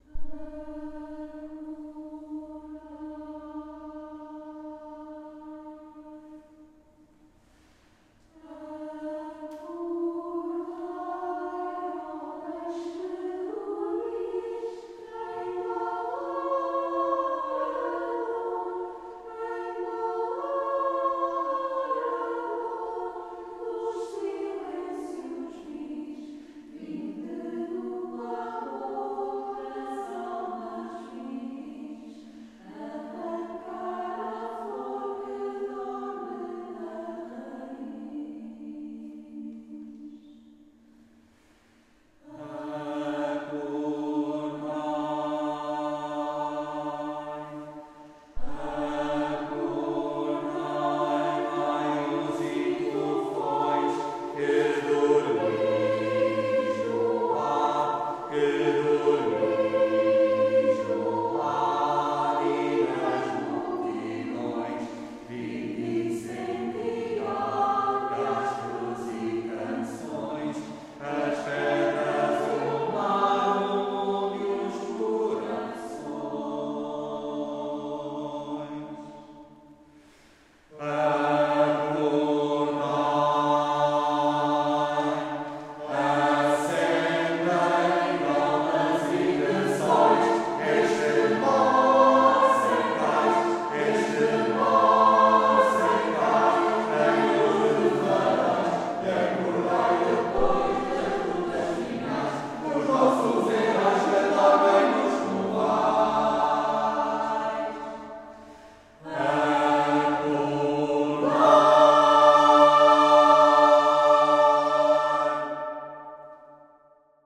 Cantamos ‘a capella’ acompanhadas/os por instrumentos solistas ou grupos instrumentais.
coro-gravacao-acordai-v2-1-1.m4a